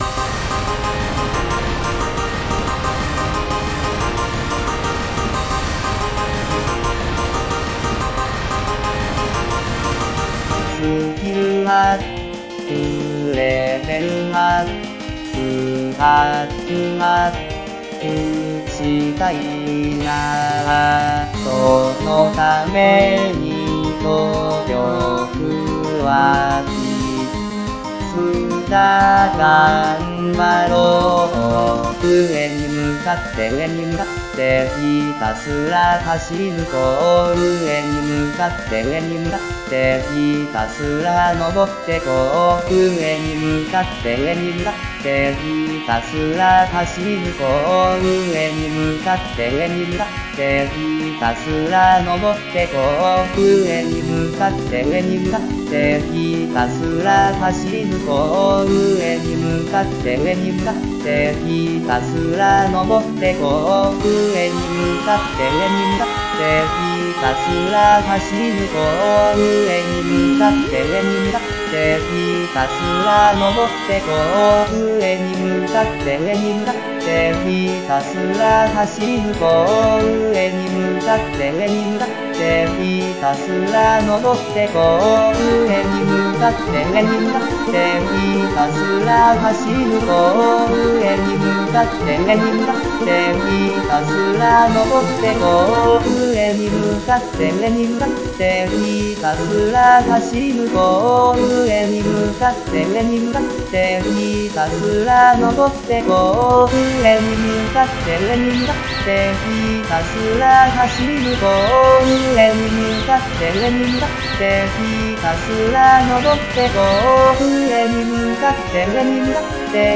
趣味は転調